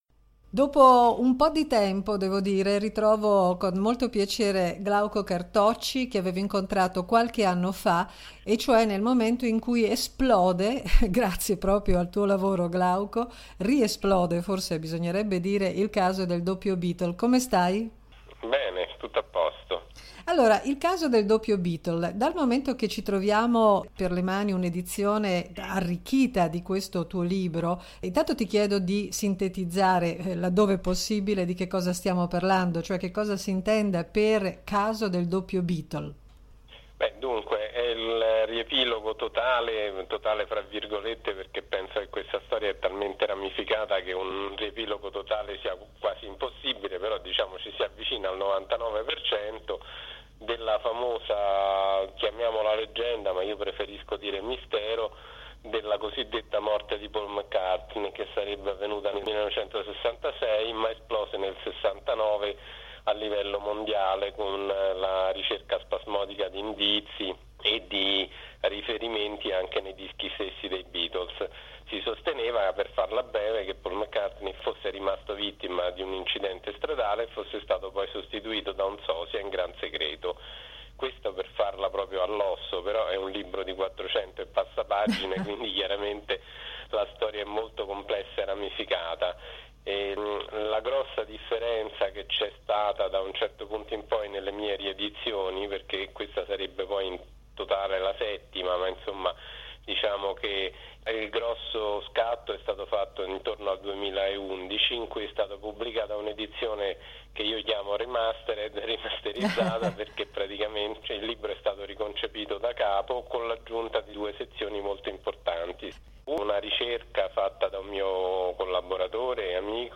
chiacchierata